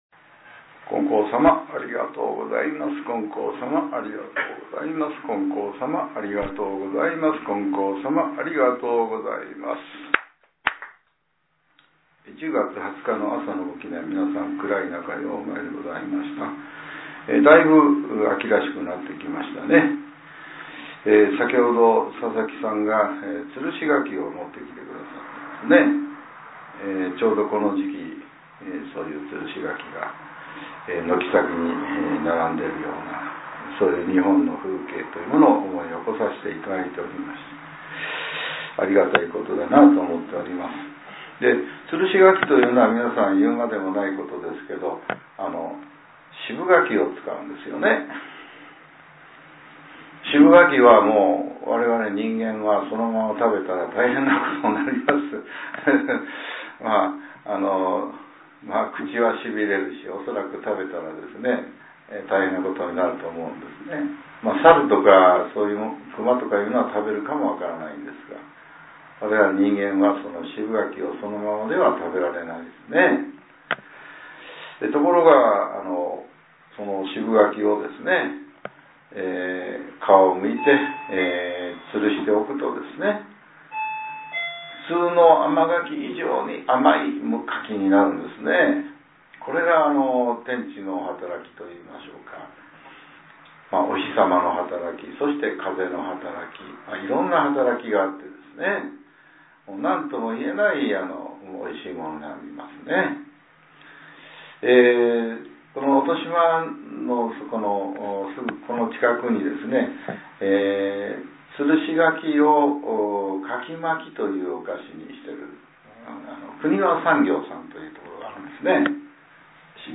令和７年１０月２０日（朝）のお話が、音声ブログとして更新させれています。